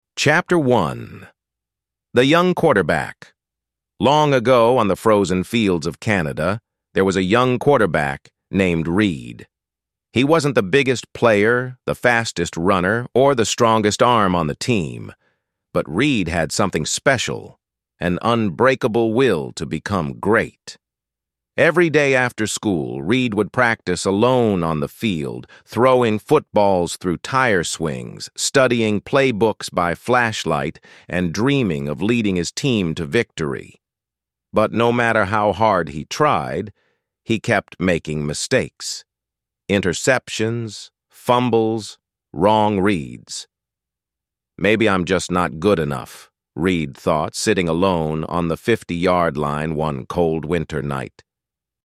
ElevenLabs_2026-01-27T14_57_36_Adam – Dominant, Firm_pre_sp89_s41_sb48_se0_b_m2